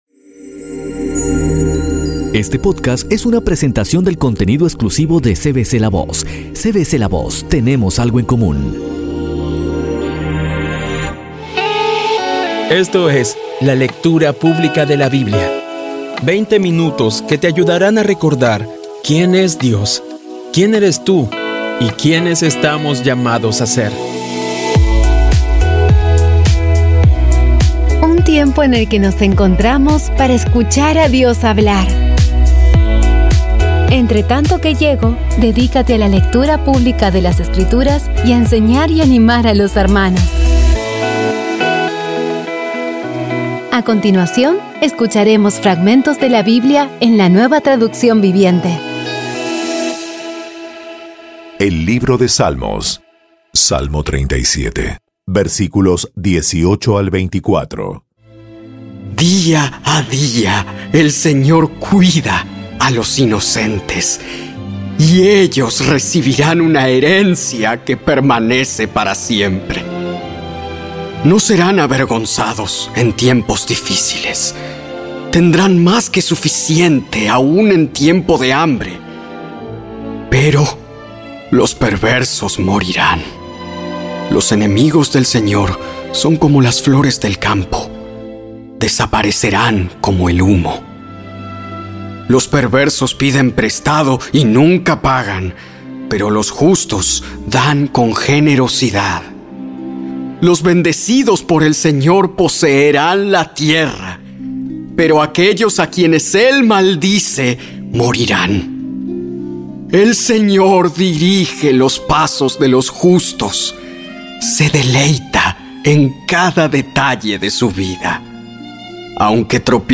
Con tan solo veinte minutos diarios, vas cumpliendo con tu propósito de estudiar la Biblia completa en un año. Poco a poco y con las maravillosas voces actuadas de los protagonistas vas degustando las palabras de esa guía que Dios nos dio.